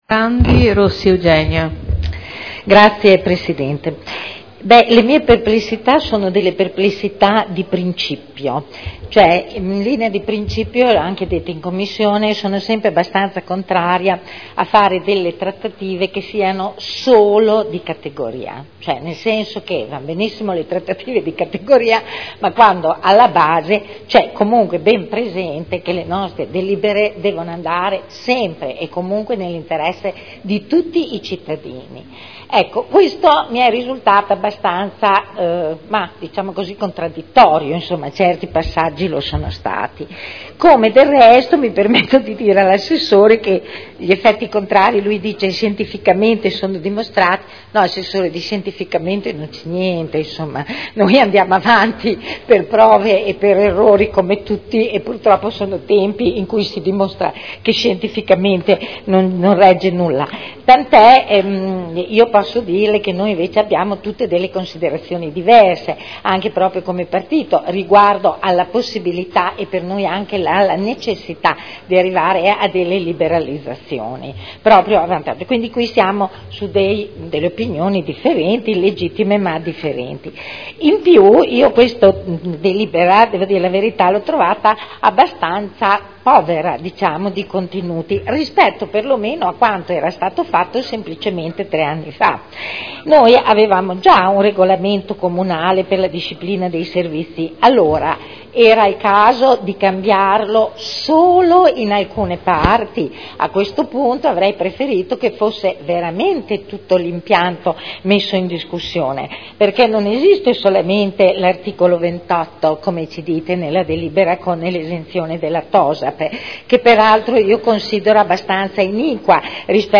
Seduta del 04/04/2011. Dibattito su delibera: Servizi di trasporto pubblico non di linea: taxi e noleggio con conducenti di veicoli fino a 9 posti – Approvazione nuovo Regolamento comunale e modifica art. 28 del Regolamento per l’applicazione della tassa per l’occupazione di spazi ed aree pubbliche e per il rilascio delle concessioni di suolo pubblico (Commissione consiliare del 15 e del 29 marzo 2011)